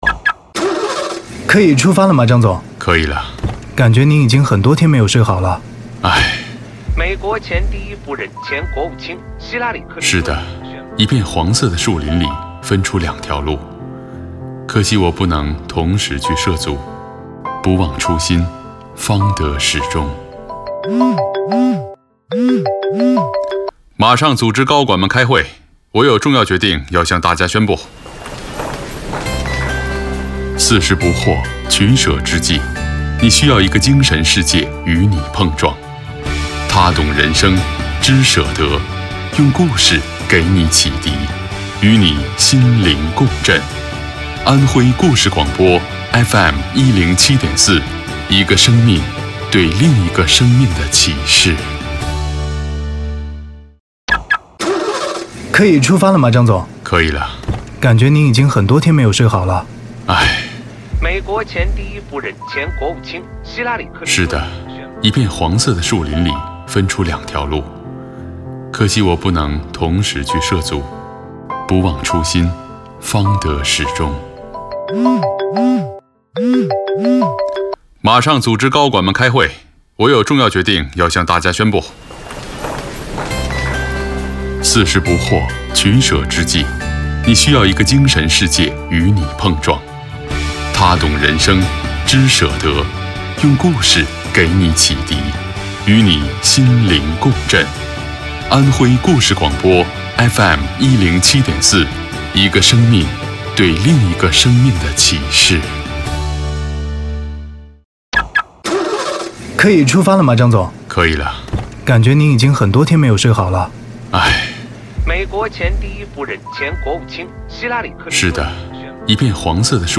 国语青年大气浑厚磁性 、沉稳 、积极向上 、时尚活力 、男广告 、600元/条男11 国语 男声 广告 马自达3昂克赛拉汽车广告 大气浑厚磁性|沉稳|积极向上|时尚活力